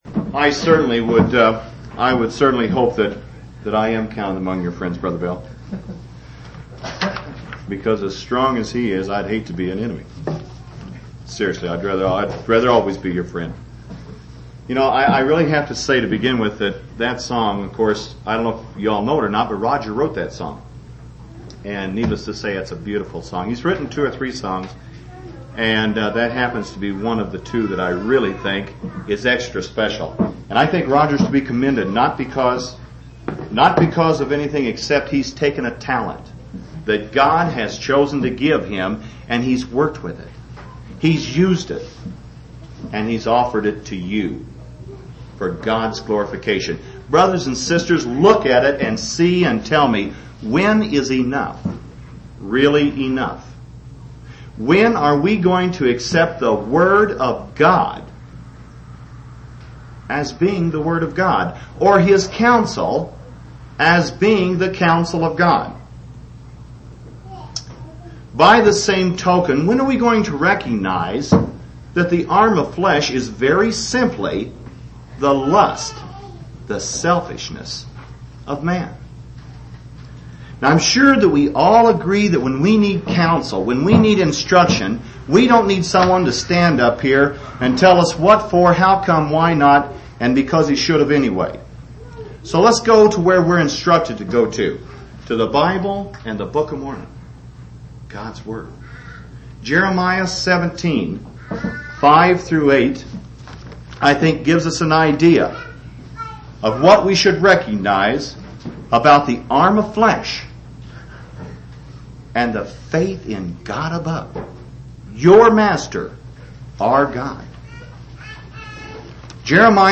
6/13/1983 Location: Colorado Reunion Event